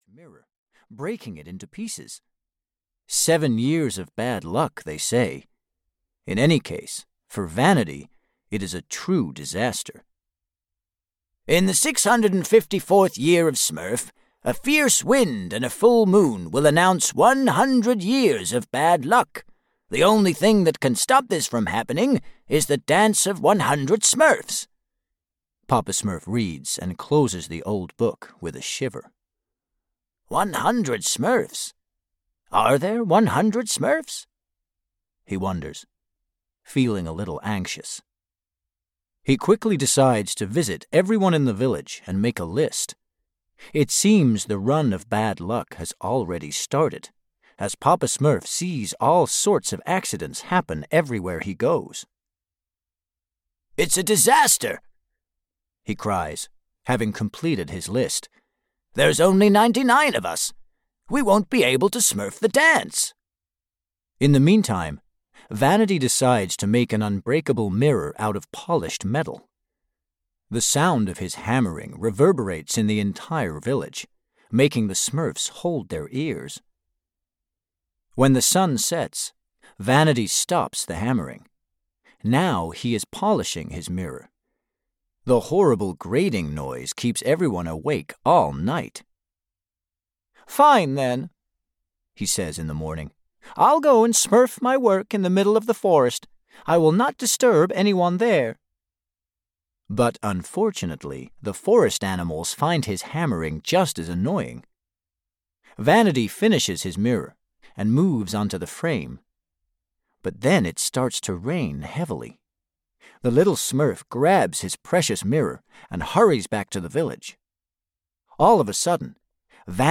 Smurfs: Storytime Collection 4 (EN) audiokniha
Ukázka z knihy